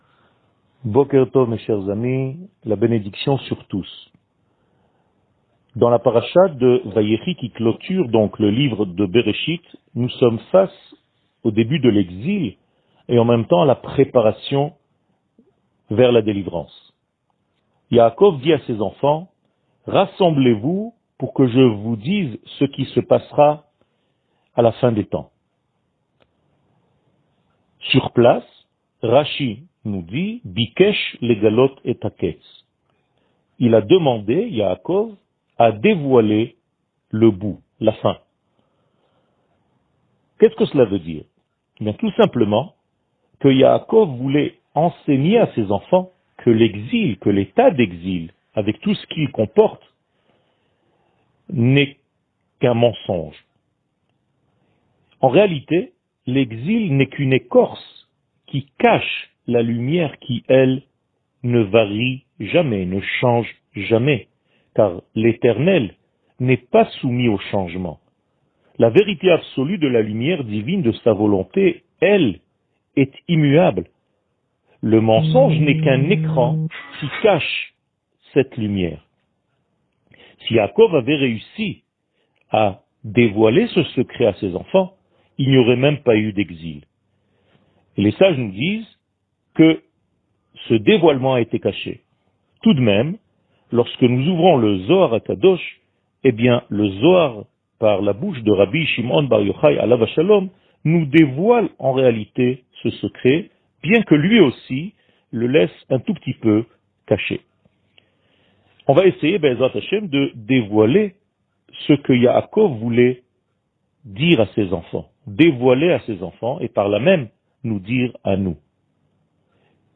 שיעור מ 30 דצמבר 2020
שיעורים קצרים